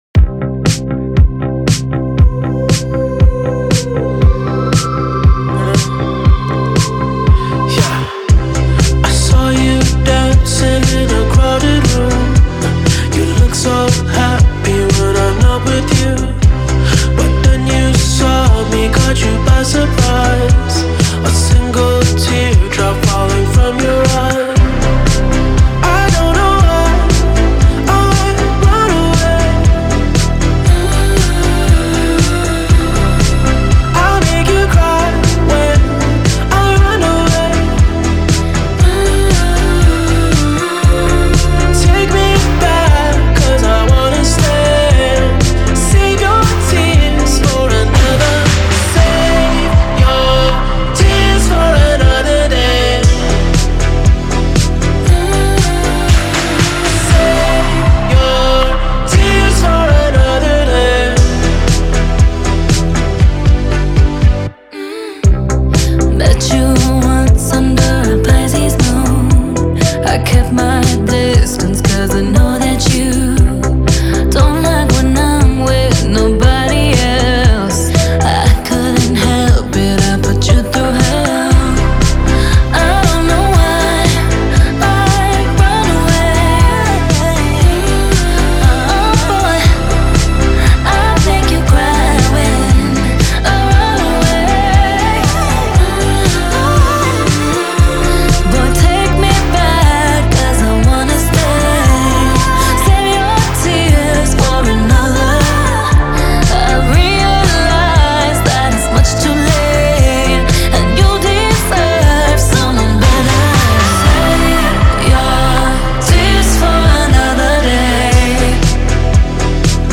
melodious voice